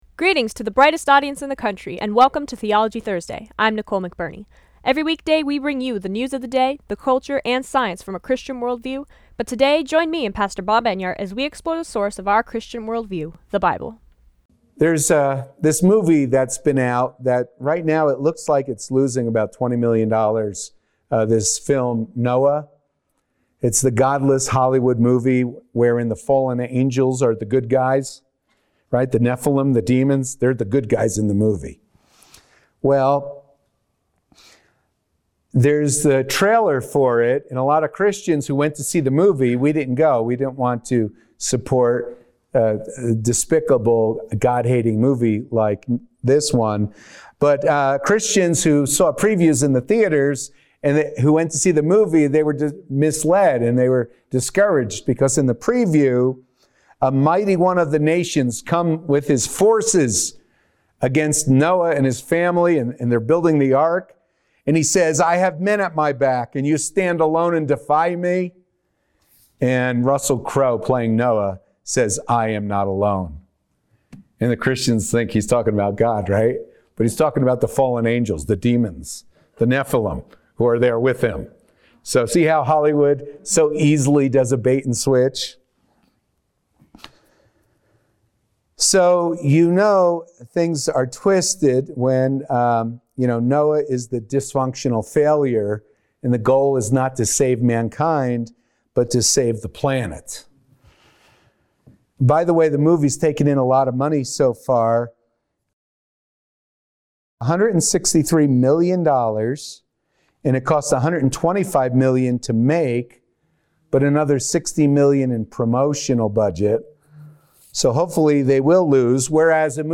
Friday's Broadcast